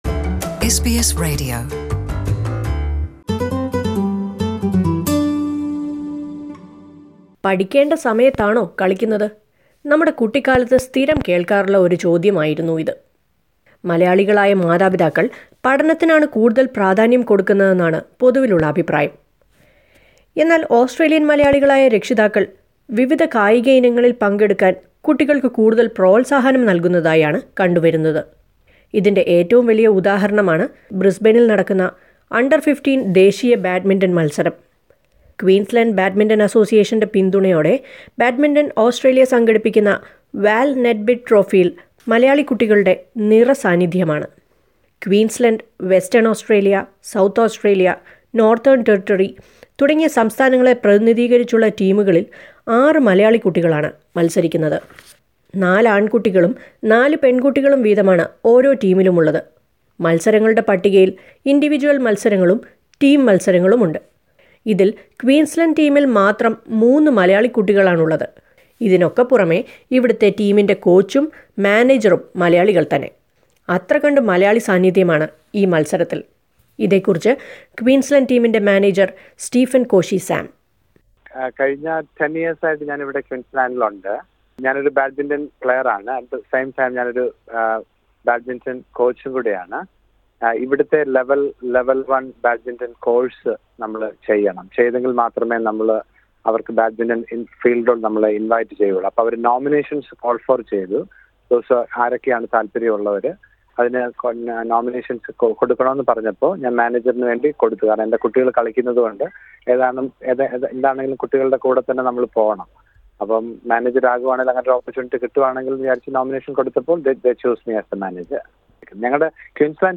A number of Malayalee kids were selected to play in the U-15 national level badminton tournament held Brisbane. Here is a report on this.